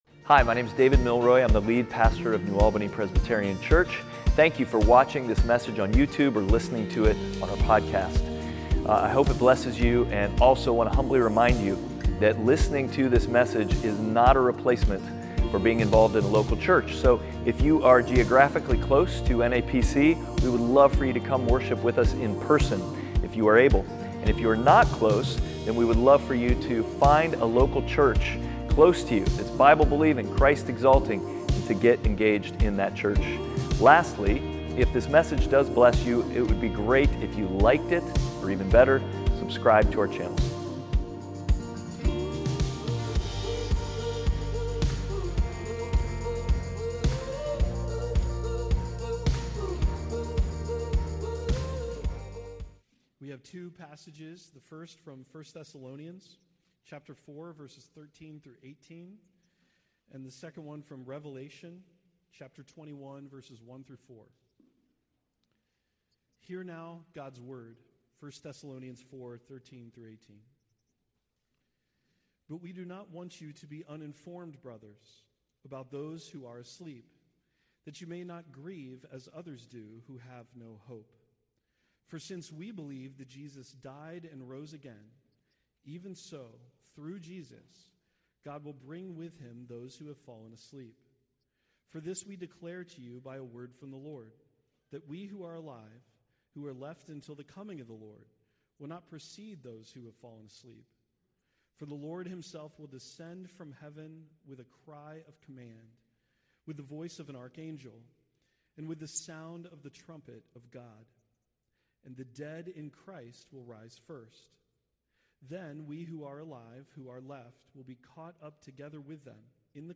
Passage: Revelation 21:1-4, 1 Thessalonians 4:13-18 Service Type: Sunday Worship